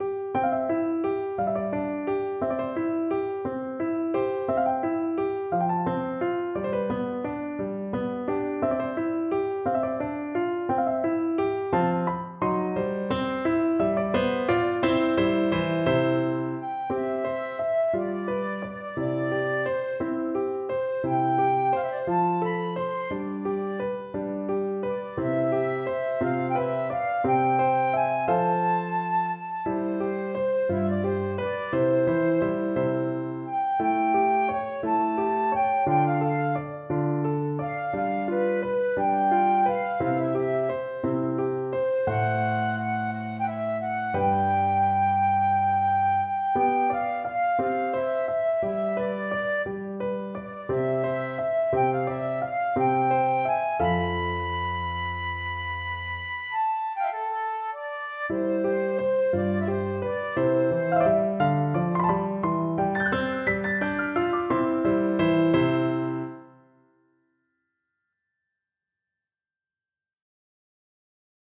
Free Sheet music for Flute
Flute
C major (Sounding Pitch) (View more C major Music for Flute )
Andantino .=58 (View more music marked Andantino)
6/8 (View more 6/8 Music)
Classical (View more Classical Flute Music)